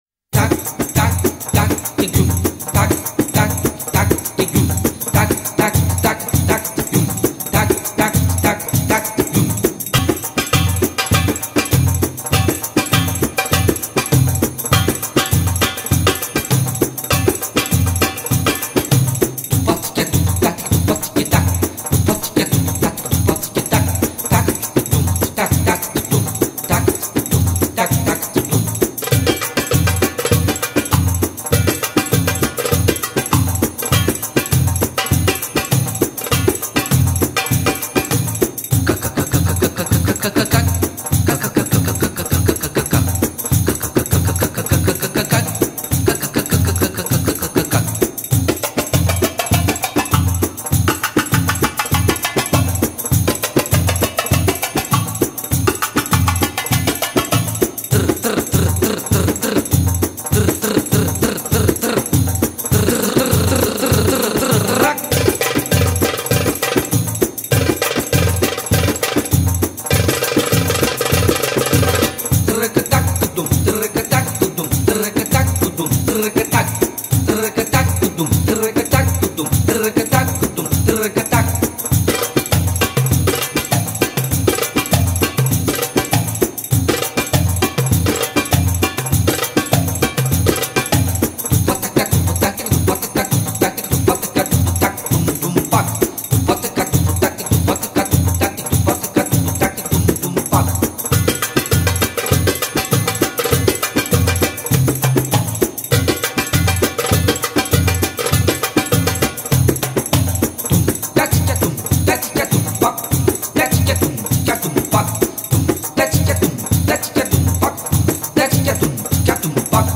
دوم تک تک دوم تک تک عربی بی کلام